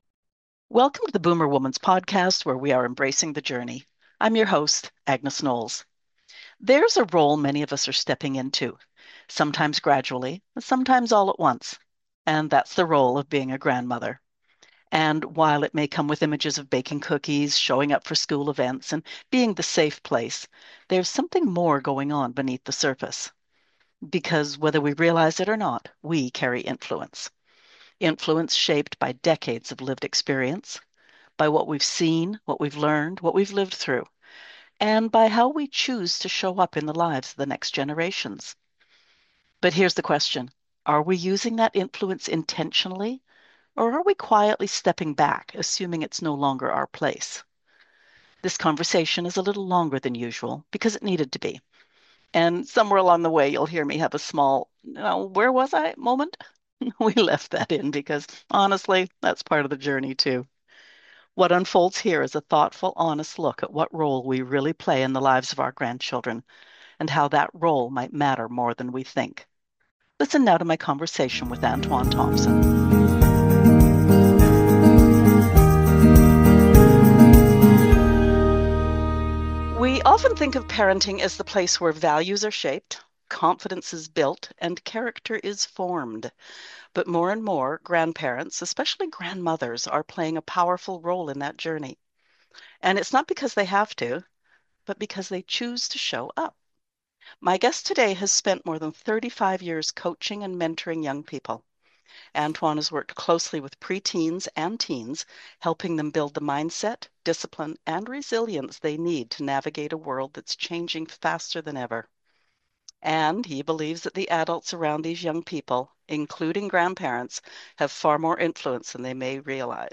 This is a conversation about presence, perspective, and the kind of legacy that isn’t announced… but felt. What’s changed for today’s preteens and teens—and what hasn’t The growing gap in structure, accountability, and communication Why kids feel unheard—and how to change that The difference between guiding and overstepping